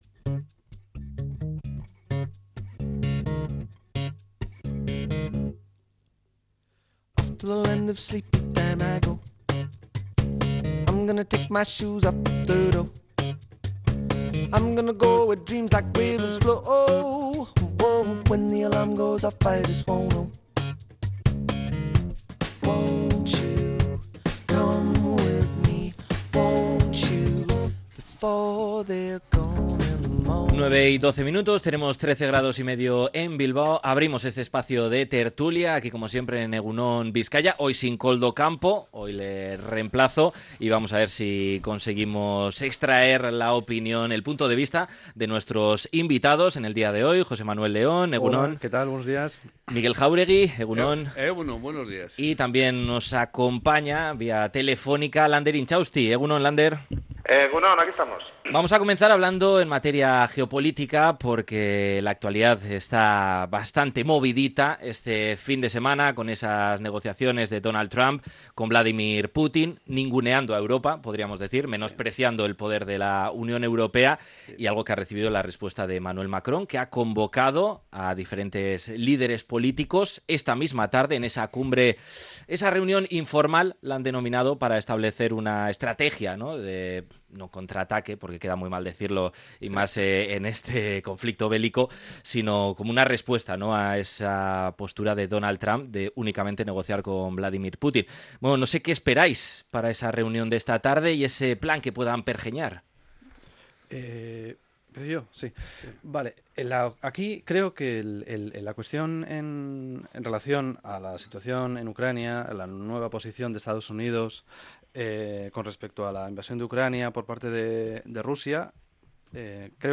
La tertulia 17-02-25.